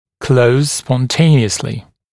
[kləuz spɔn’teɪnɪəslɪ][клоуз спон’тэйниэсли]самопроизвольно закрываться, спонтанно закрываться